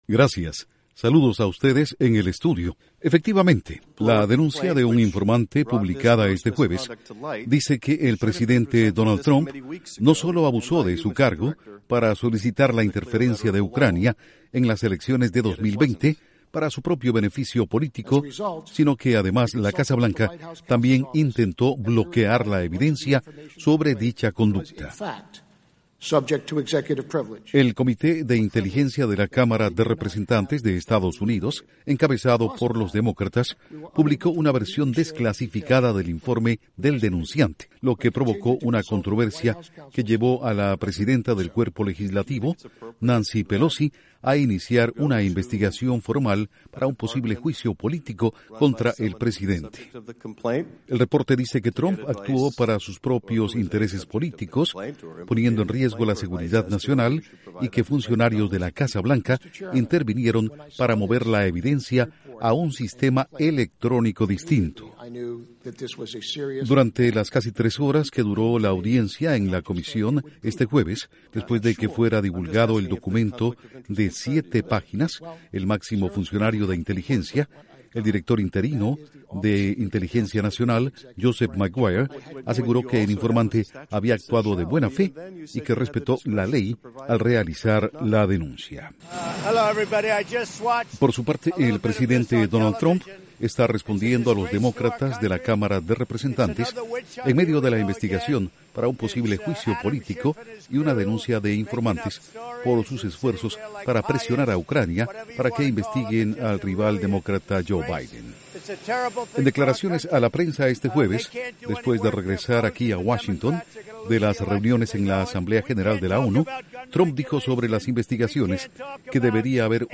Duración: 2:30 Audios de legisladores de EE.UU. Repuesta de Donald Trump/Presidente EE.UU.